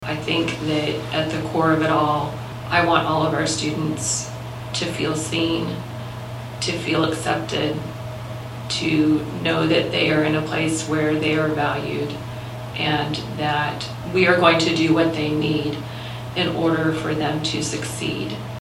Tensions rose between the public during Wednesday’s USD 383 Manhattan-Ogden school board meeting.
Later on in the meeting, the board responded to the incident, with Hagemeister saying the incident left her feeling sad.